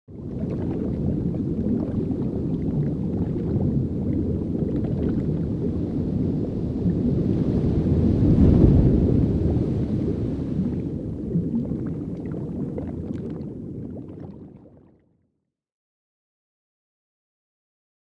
جلوه های صوتی
دانلود صدای وال 20 از ساعد نیوز با لینک مستقیم و کیفیت بالا
برچسب: دانلود آهنگ های افکت صوتی انسان و موجودات زنده دانلود آلبوم صدای حیوانات آبی از افکت صوتی انسان و موجودات زنده